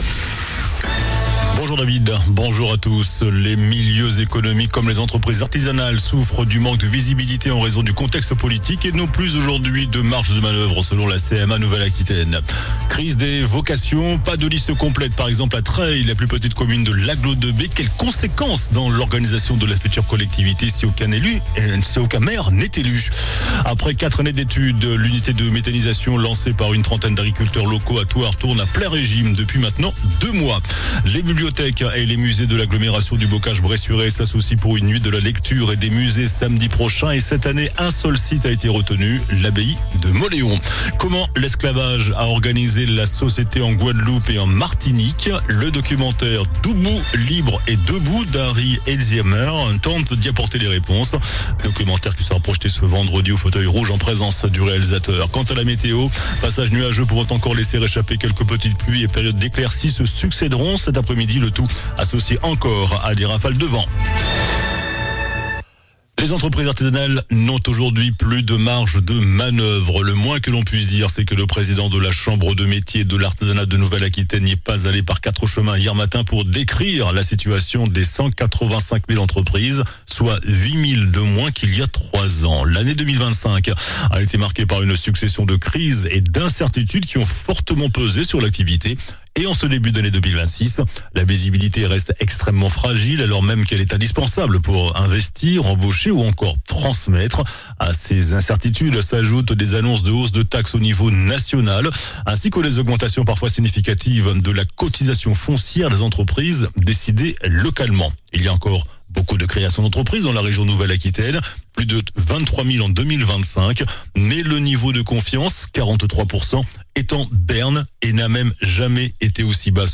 JOURNAL DU MERCREDI 21 JANVIER ( MIDI )